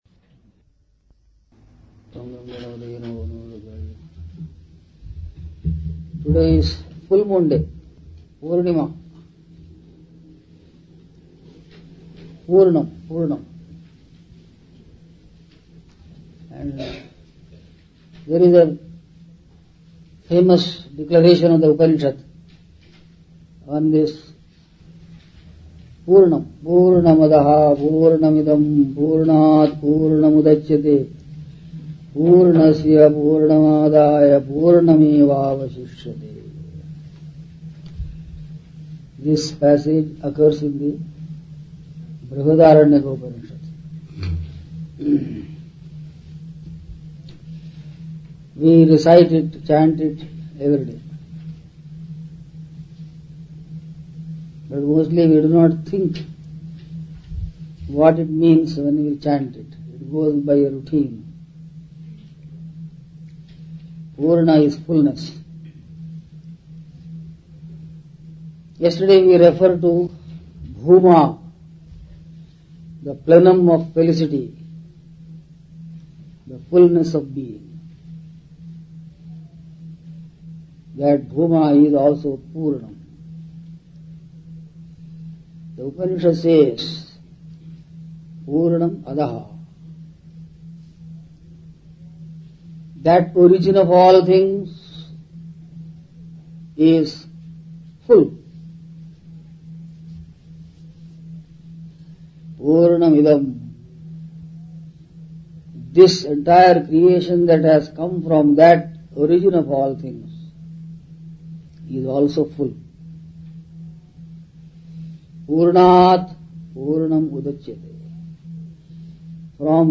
Upanishads – A series of talks on the Upanishads.